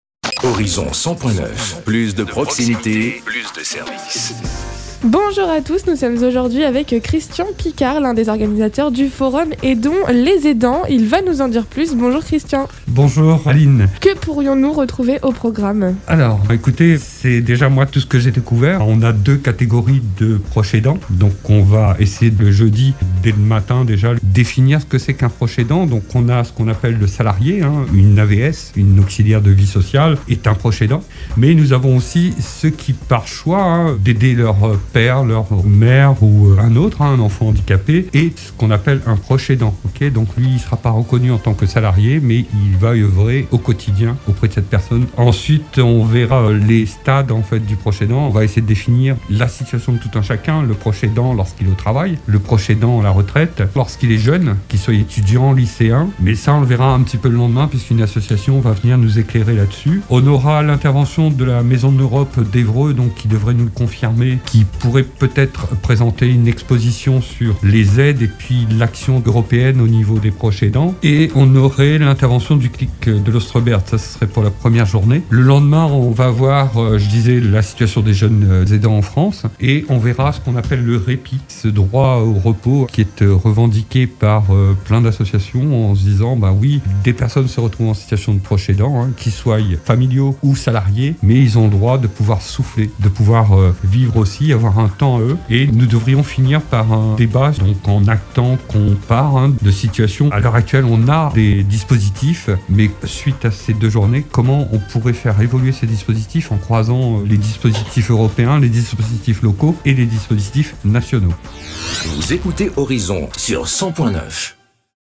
Actualités, Articles, Les matins normands, notre région